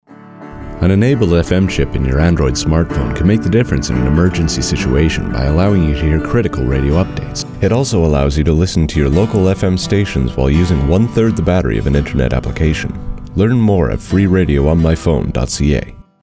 Type: PSA